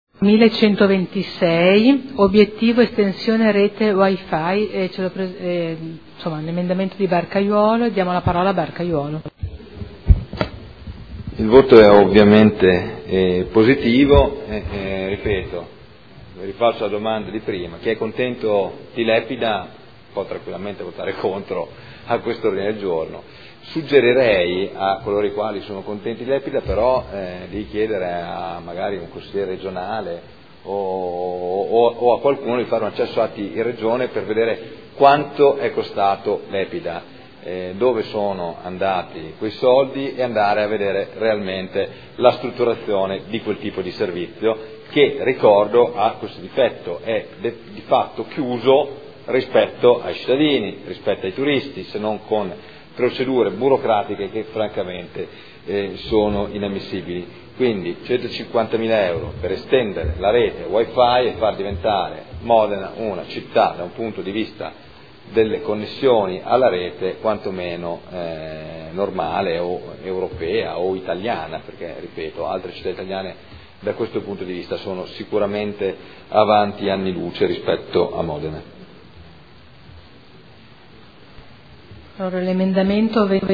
Seduta del 13 marzo. Dichiarazioni di voto su singoli emendamenti o complessive